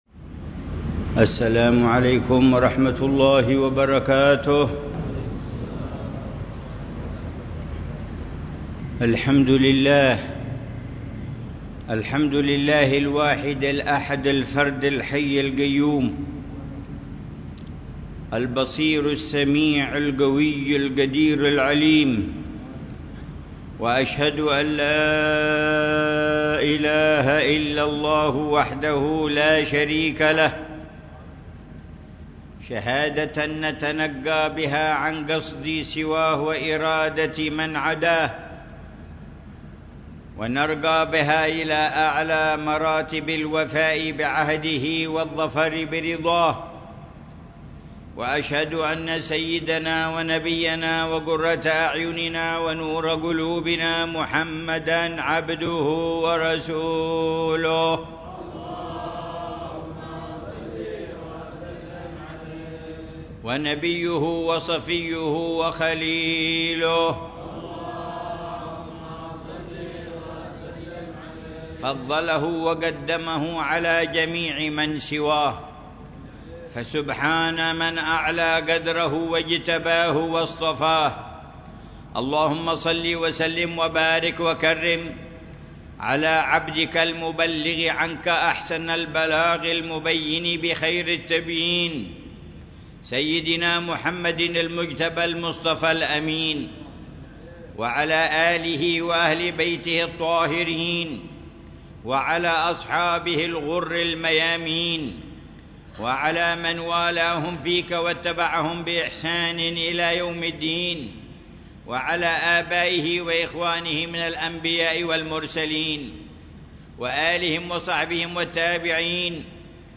خطبة الجمعة للعلامة الحبيب عمر بن محمد بن جفيظ في جامع التوفيق، بحارة التوفيق، عيديد، مدينة تريم، 20 شوال 1446هـ بعنوان: